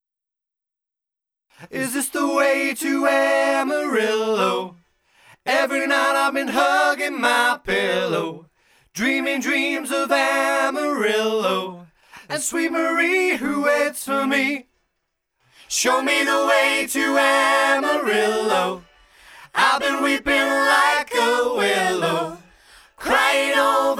Backing Vocals only with FX Pop (1970s) 3:20 Buy £1.50